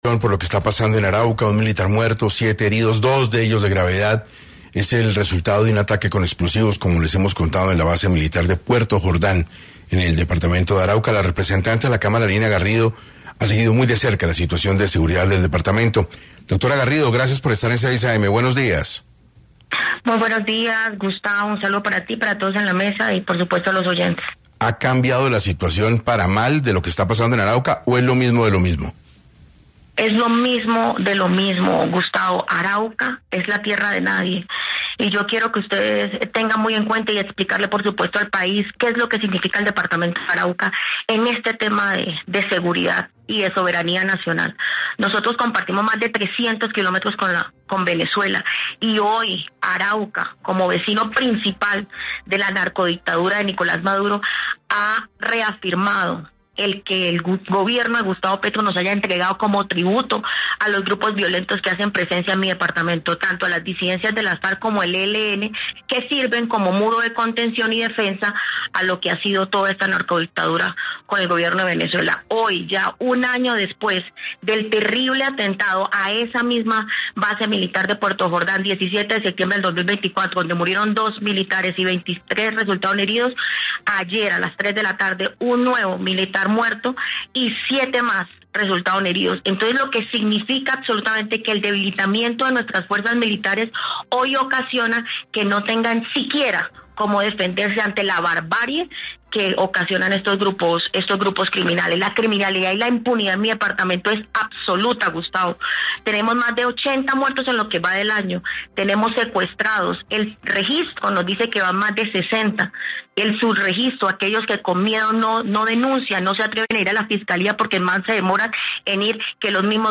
En 6 AM de Caracol Radio, estuvo Lina Garrido, representante a la Cámara por Arauca y manifestó su preocupación en los esquemas de seguridad y habló sobre las amenazas que ha recibido por parte del ELN